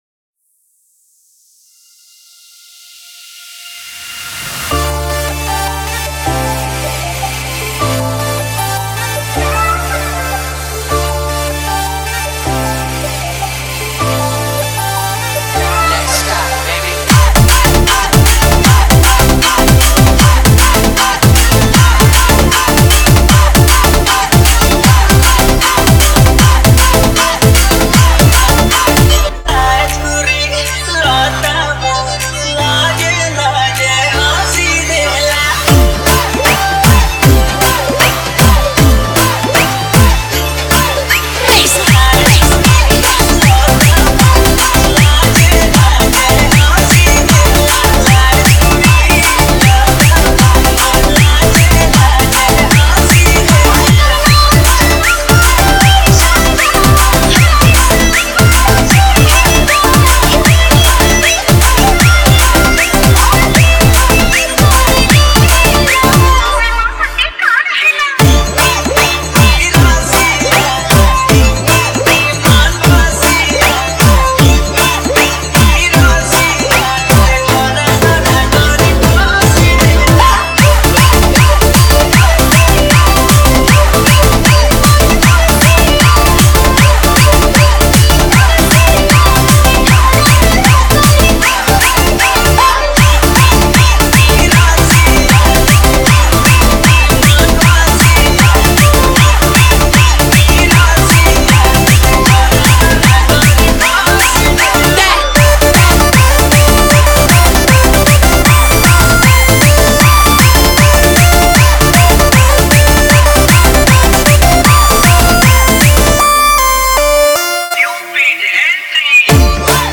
Category: Bbsr Spl All Dj Remix Songs 2021